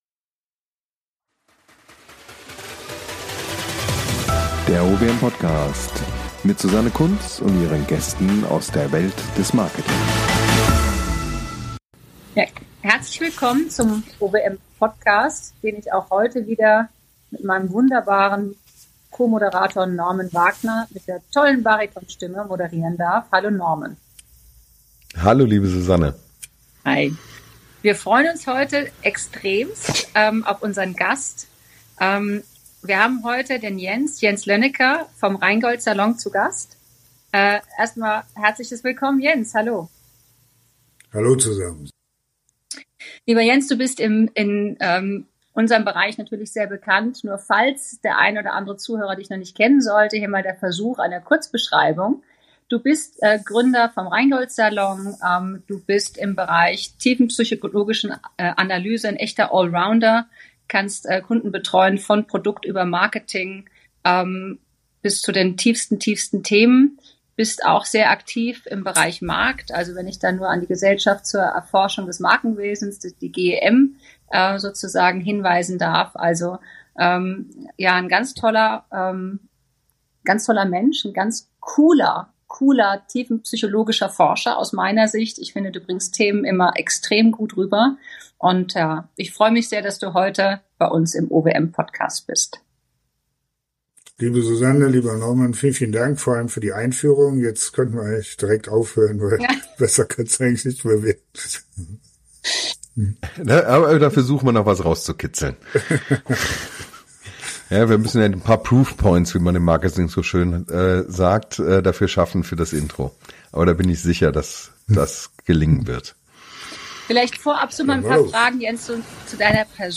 im Gesrpäch mit der OWM